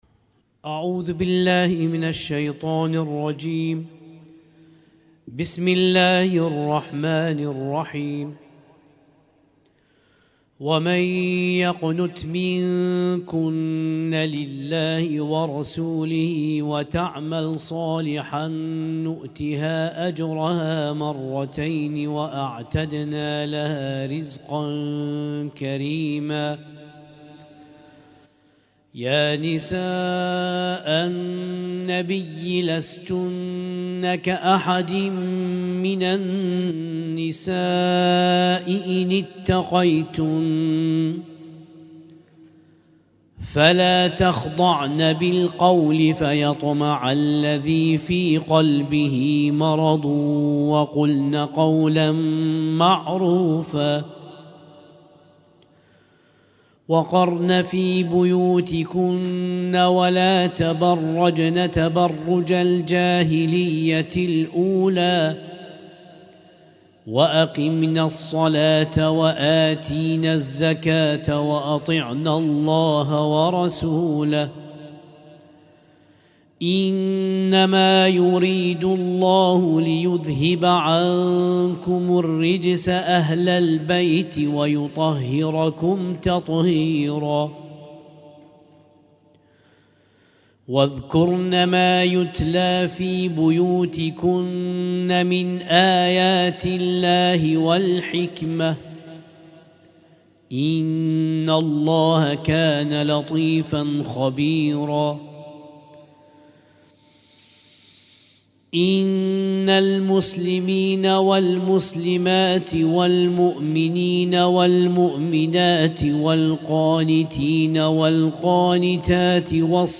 اسم التصنيف: المـكتبة الصــوتيه >> القرآن الكريم >> القرآن الكريم - شهر رمضان 1446